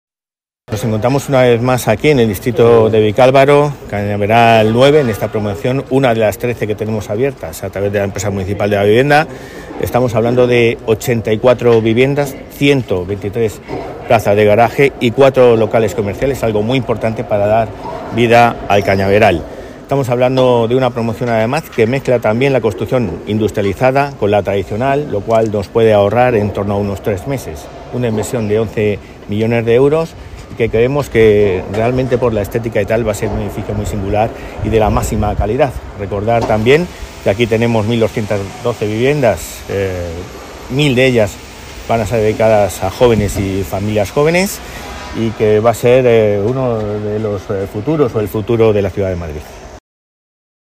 Nueva ventana:Álvaro González en su visita a las obras de la nueva promoción Cañaveral 9